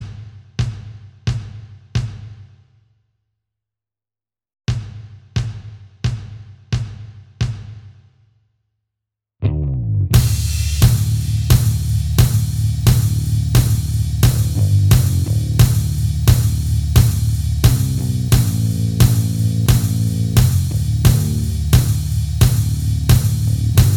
Minus Guitars Rock 3:46 Buy £1.50